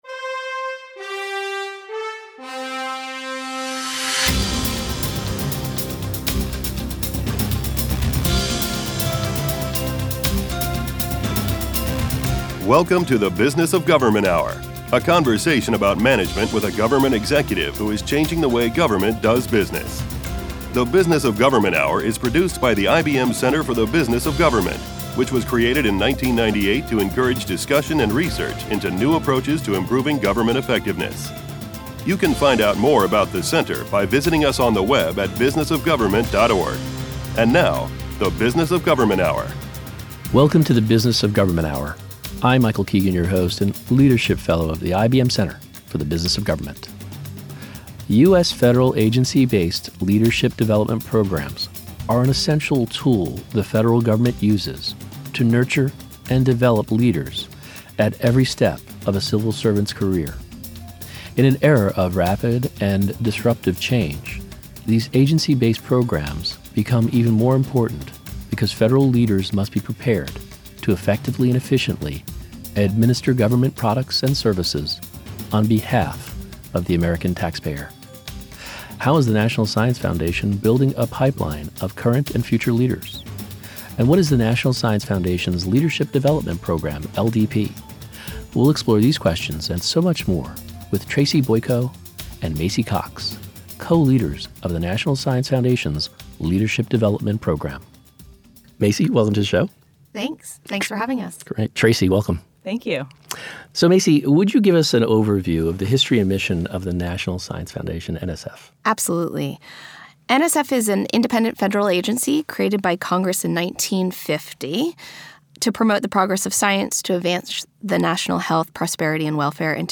Interviews | IBM Center for The Business of Government